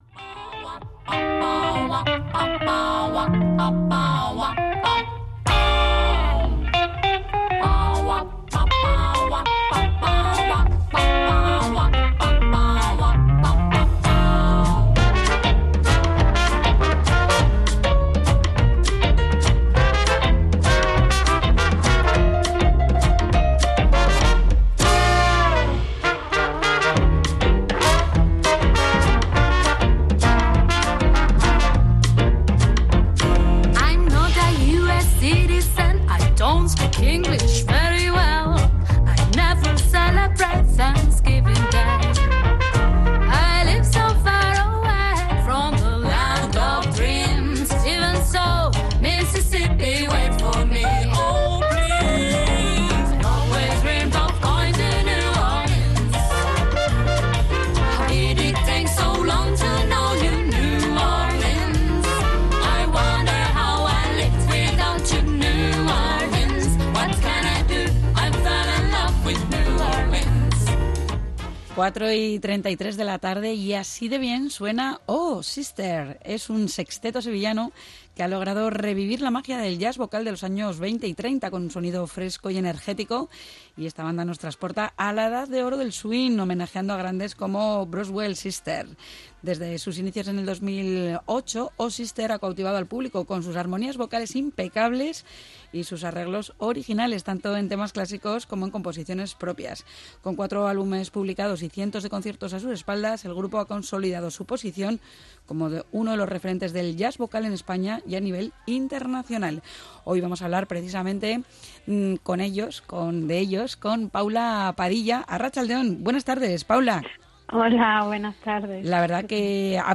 O Sister! es un sexteto sevillano que ha logrado revivir la magia del jazz vocal de los años 20 y 30. Con un sonido fresco y enérgico, la banda nos transporta a la edad de oro del swing, homenajeando a grandes como las Boswell Sisters.